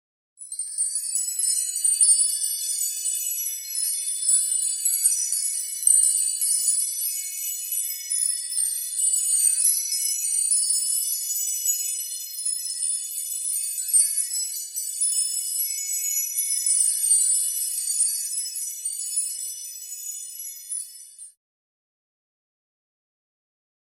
На этой странице собраны умиротворяющие звуки Бога и ангелов — небесные хоры, божественные мелодии и атмосферные треки для медитации, релаксации или творческих проектов.